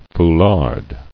[fou·lard]